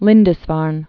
(lĭndĭs-färn)